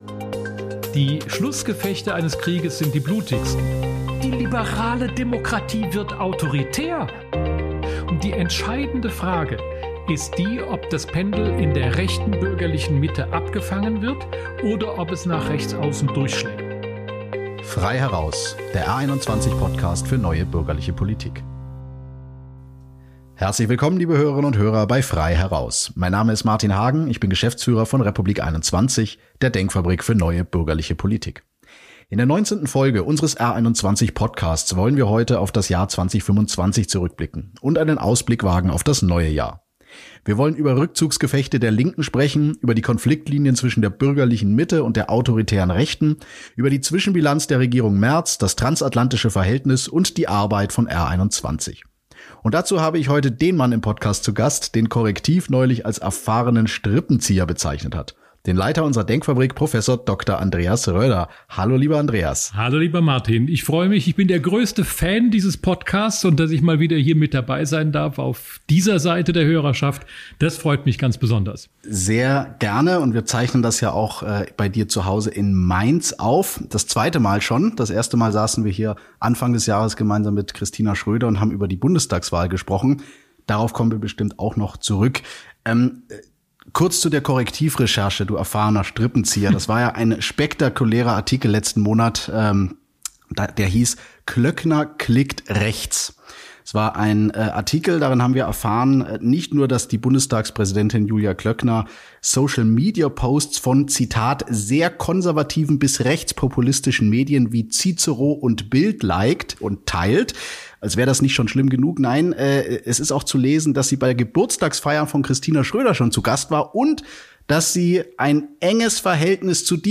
Im Gespräch mit Martin Hagen spricht der Historiker über das Ende der grünen Hegemonie, die Konfliktlinien zwischen der bürgerlichen Mitte und der autoritären Rechten, die Zwischenbilanz der Regierung Merz und das transatlantische Verhältnis. Statt der „Brandmauer“ zur AfD plädiert Rödder für rote Linien und eine harte Auseinandersetzung in der Sache.